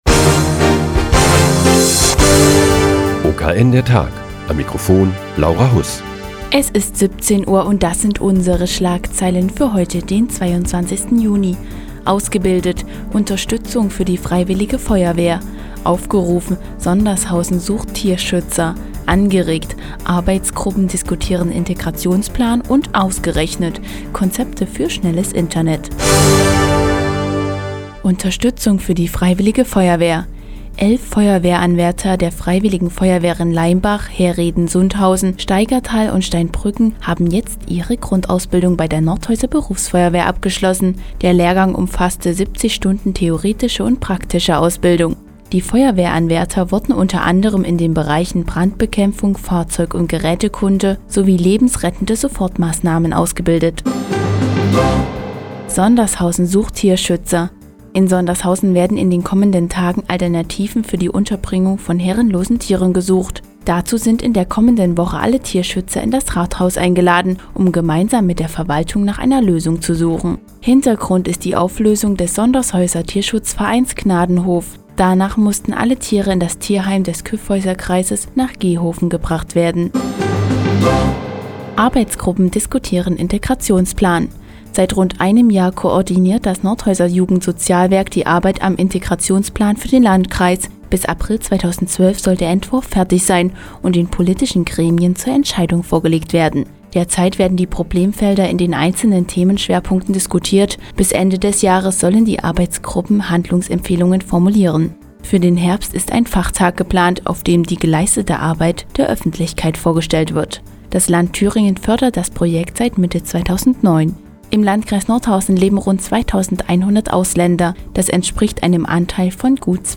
Die tägliche Nachrichtensendung des OKN ist nun auch in der nnz zu hören. Heute geht es um den Integrationsplan für den Landkreis Nordhausen und Konzepte für die Versorgung mit Breitbandinternet- Zugängen.